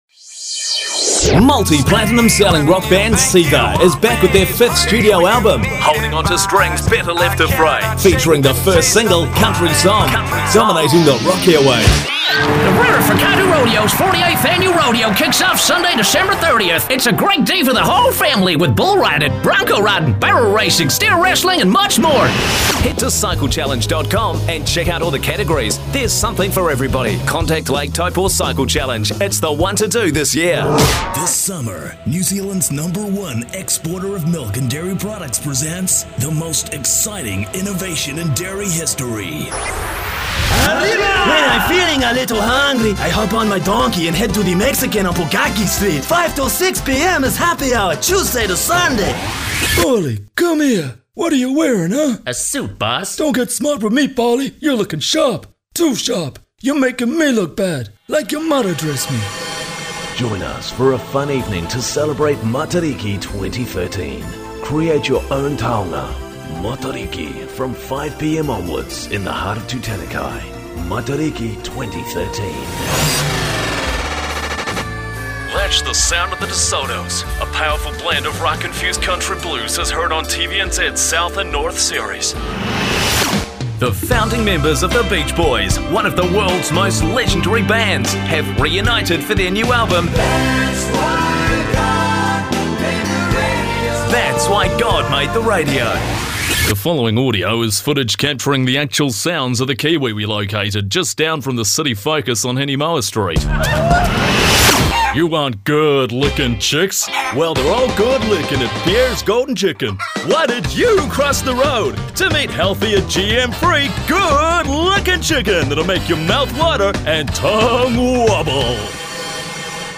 Mixed Characters - Version 2